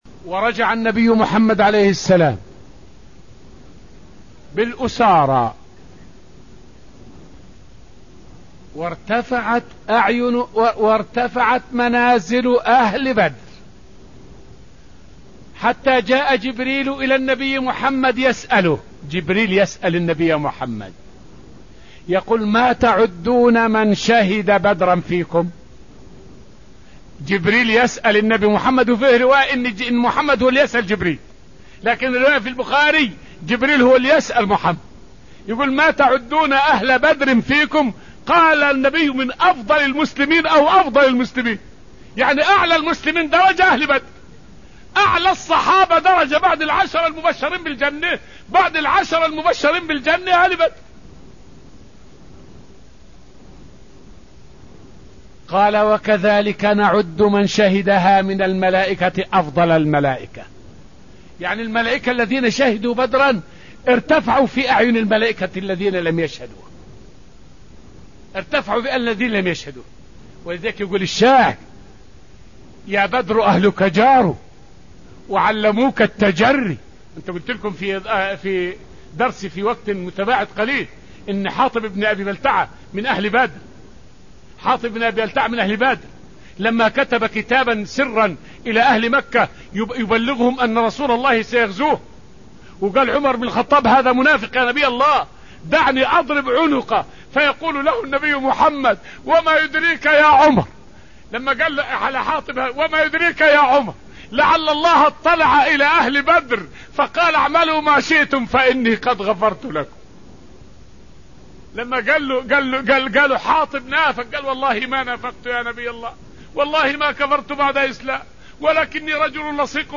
فائدة من الدرس الثاني من دروس تفسير سورة آل عمران والتي ألقيت في المسجد النبوي الشريف حول منزلة أهل بدر عند الله عز وجل.